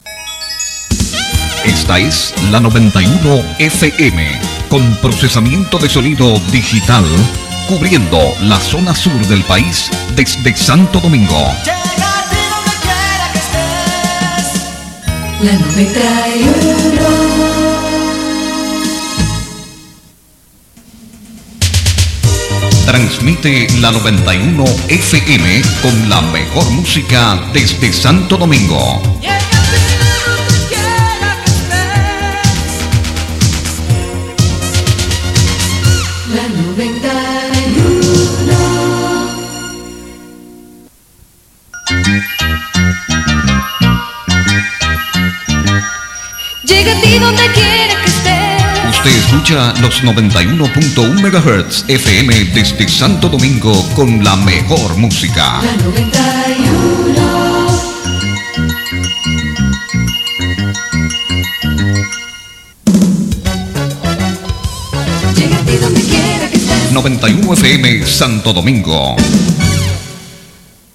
Identificaciones de la Emisora 91 FM